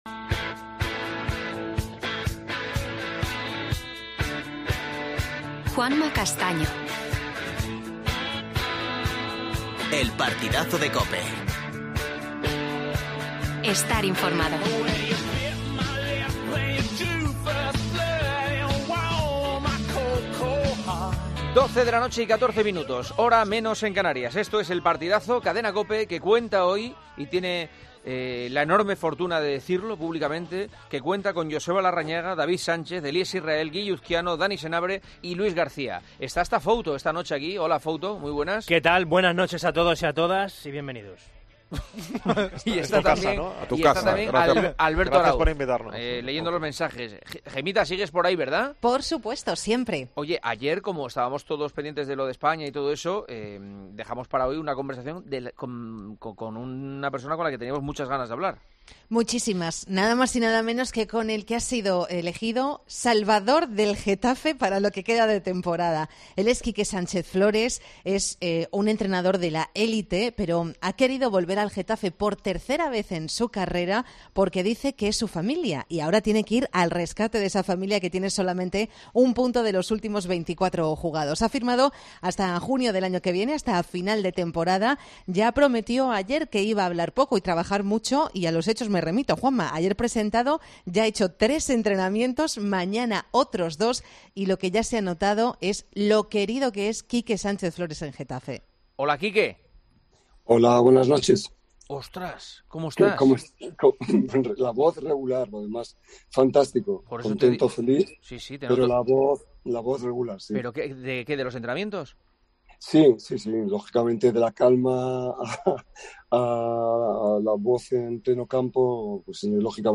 AUDIO: Entrevista al nuevo entrenador del Getafe, Quique Sánchez Flores. Tiempo de opinión.